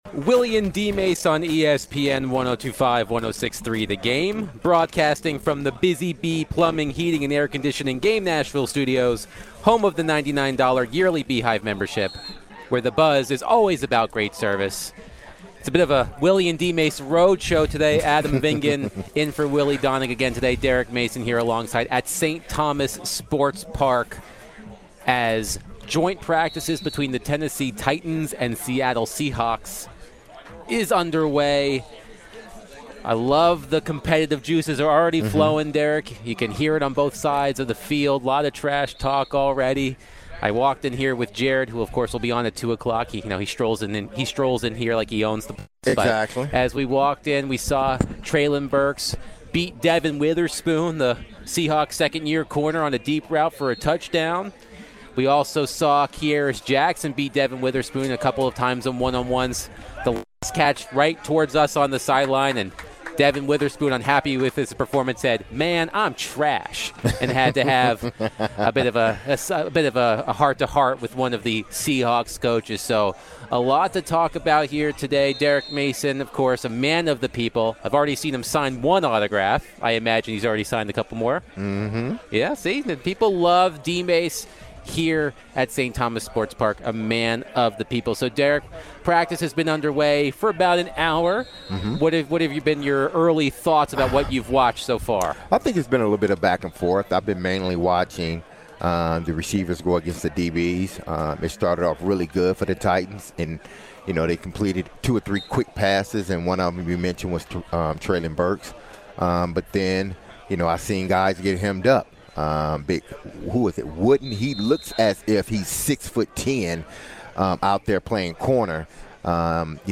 LIVE from St.Thomas Sports Park for Titans and Seahawks joint practices.